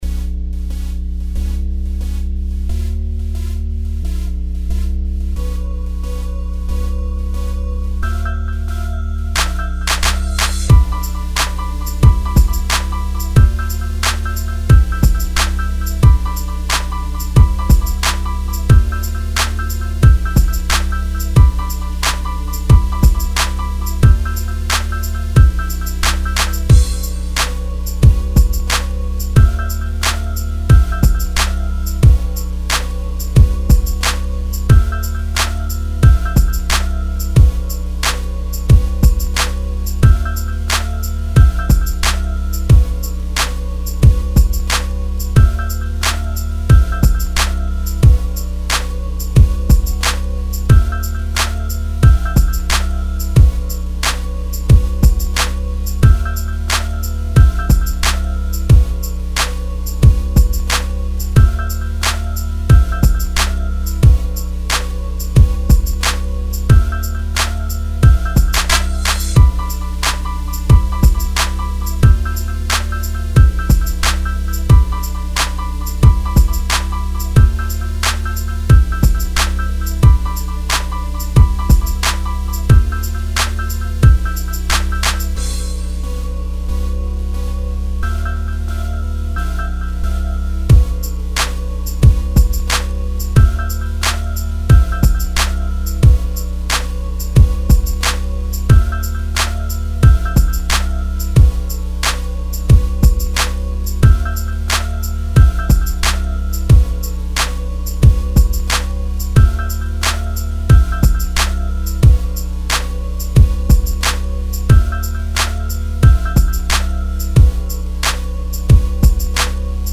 POR LANZAMIENTO TE REGALAMOS UN BEAT LIBRE !